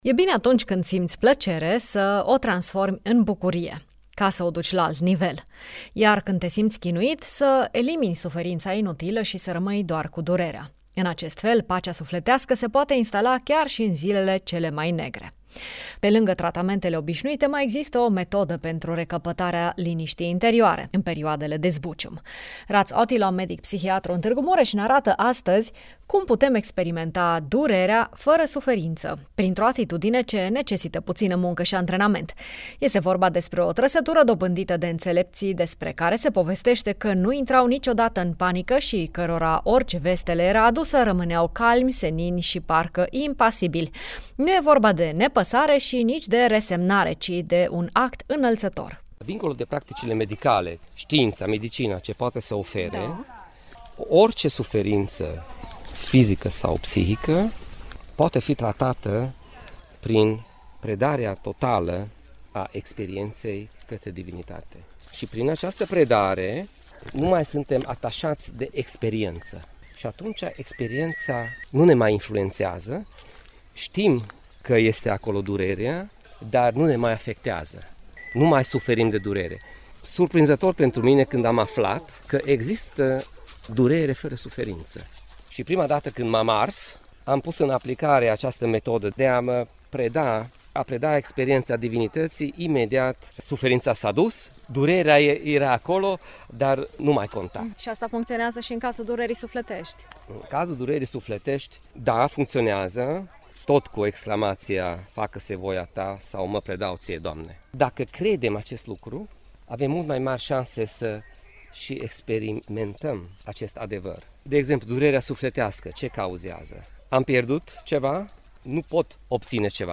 medic psihiatru